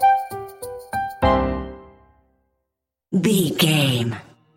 Aeolian/Minor
flute
oboe
strings
orchestra
cello
double bass
percussion
silly
circus
goofy
cheerful
perky
Light hearted
quirky